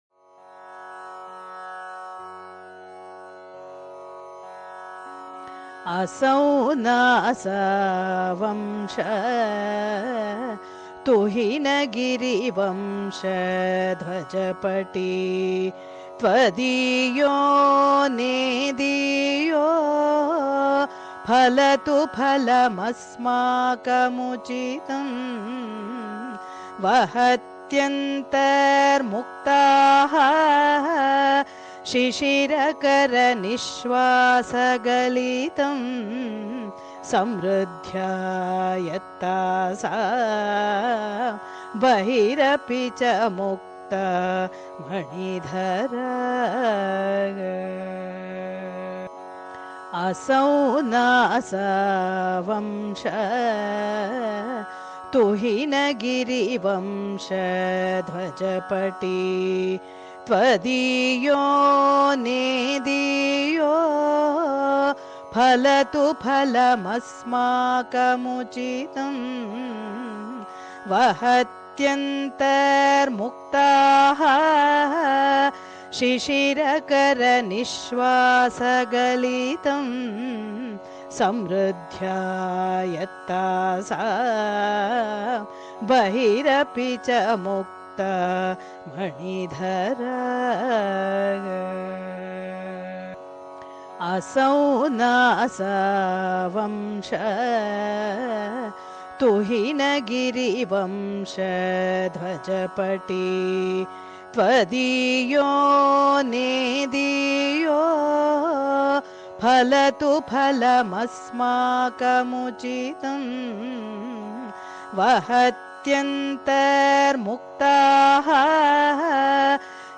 Музыкальное исполнение в примере
Рага - пуннагаварали.
Рецитация шлок Саундарья Лахари делается в соответствии с правилами поэтического размера щикхарини.
Soundarya-Lahari-Shloka-61-11-Times.mp3